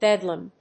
音節bed・lam 発音記号・読み方
/bédləm(米国英語), ˈbedlʌm(英国英語)/